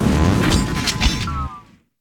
Cri de Carmadura dans Pokémon Écarlate et Violet.